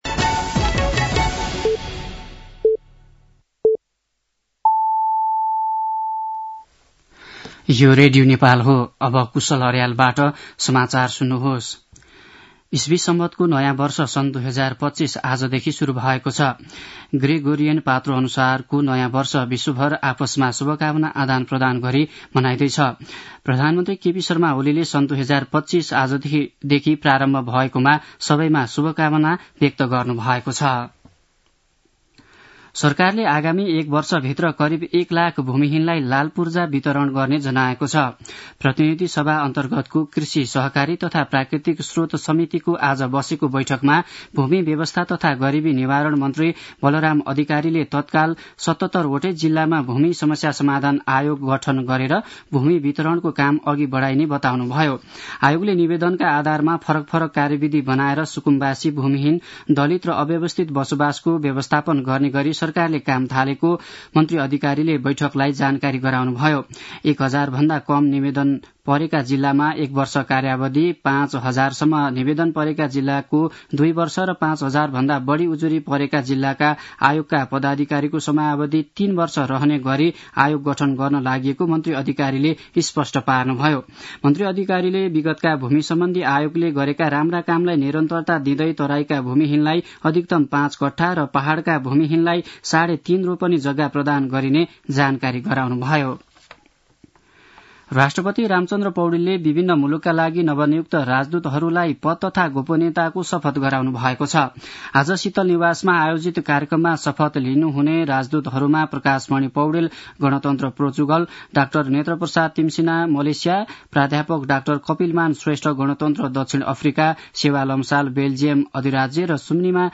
दिउँसो ४ बजेको नेपाली समाचार : १८ पुष , २०८१
4-pm-nepali-news-.mp3